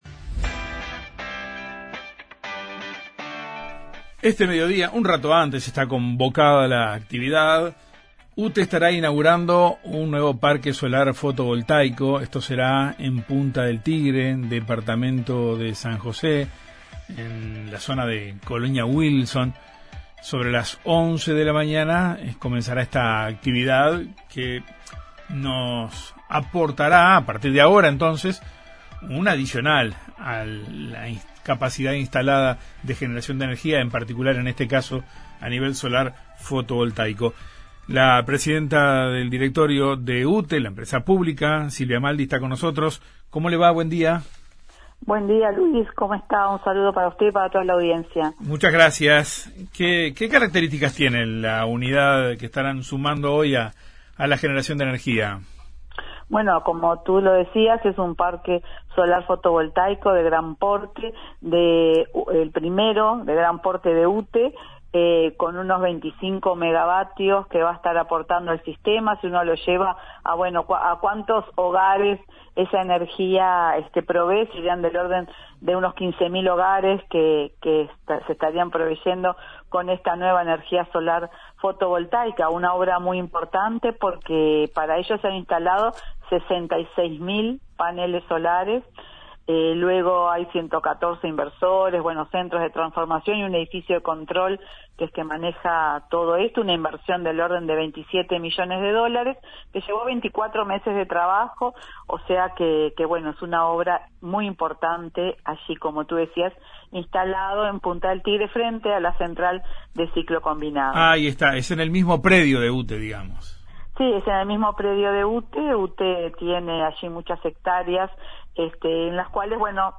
Entrevista con Silvia Emaldi